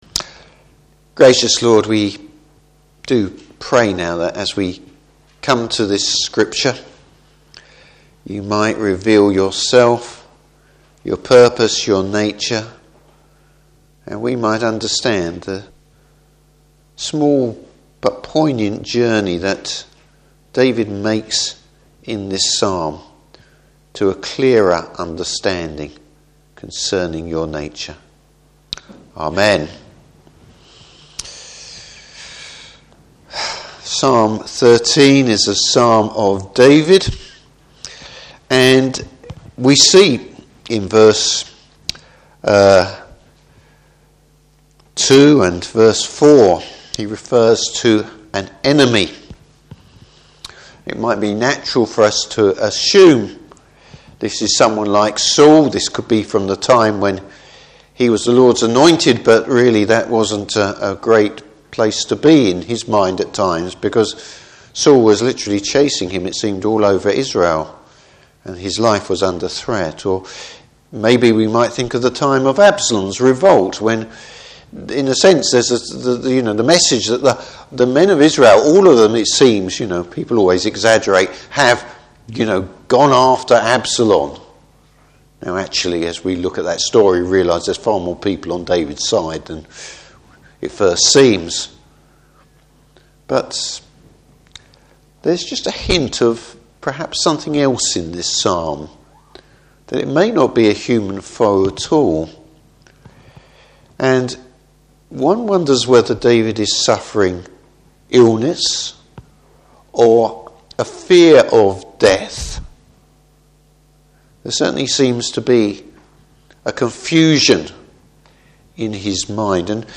Service Type: Evening Service David looks to the Lord at a difficult time.